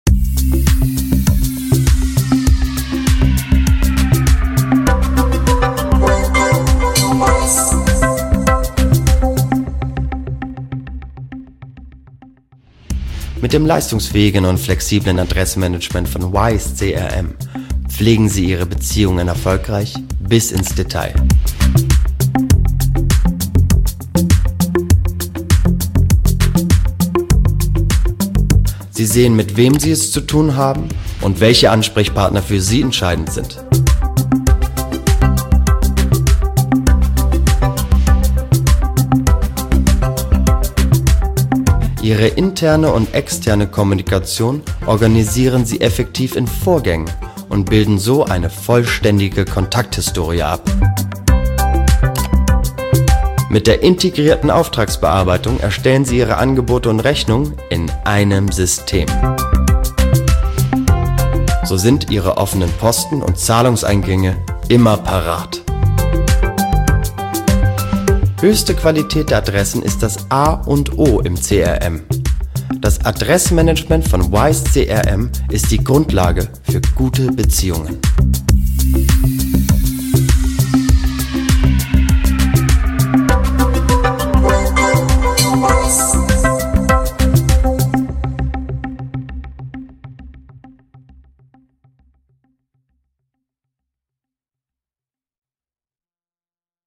warme Stimme, runde Stimme, Emotional
Sprechprobe: eLearning (Muttersprache):
war voice, round voice, emotional